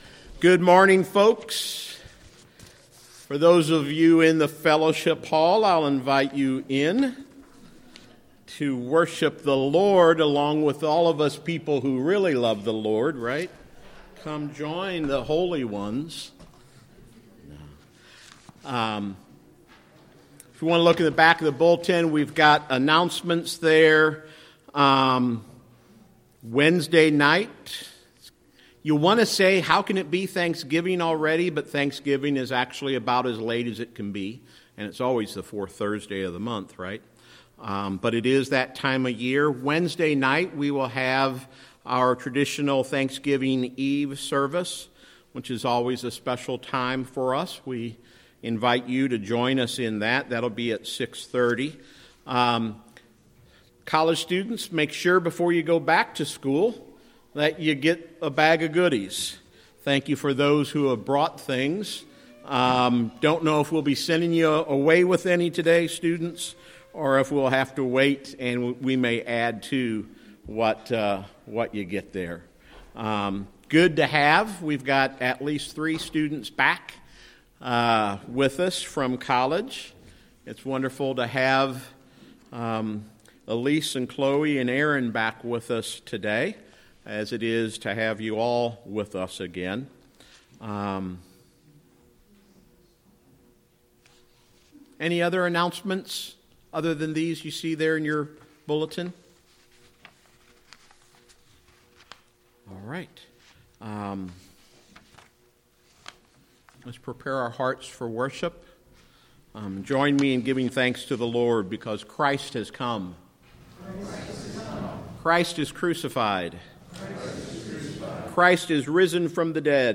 Worship Service, New Port Presbyterian Church, Luke 22:35 – 46